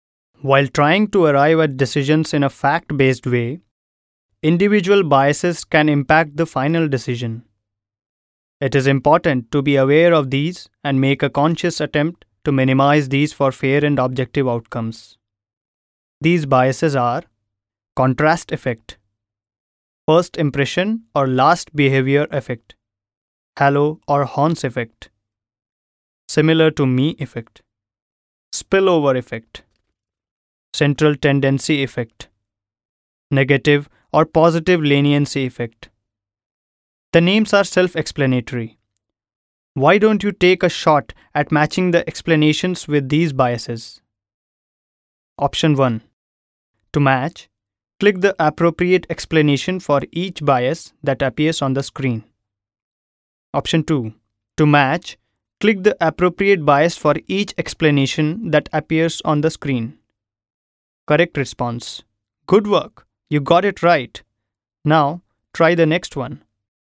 Young, middle aged voice with great command over diction in Hindi, English (Indian) and Marwari (Rajasthani).
Sprechprobe: Industrie (Muttersprache):